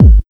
LOW135BD-L.wav